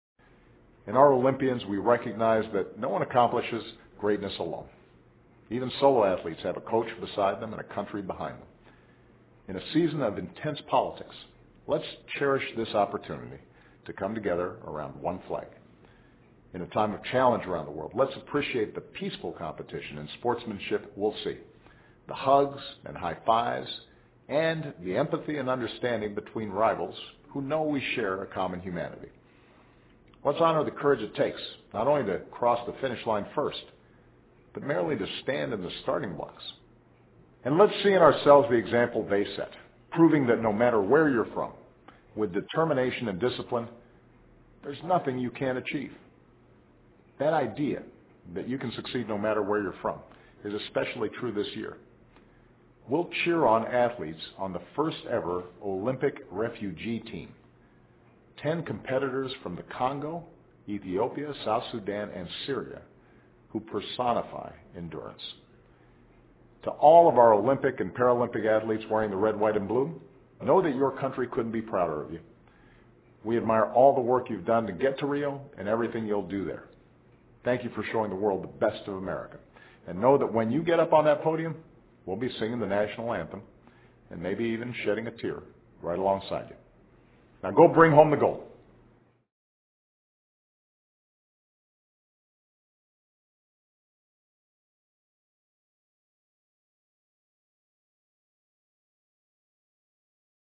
奥巴马每周电视讲话：总统呼吁向奥运会展示最好的美国（03） 听力文件下载—在线英语听力室